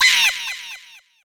SE_CHIKEN_CAR_FALL.wav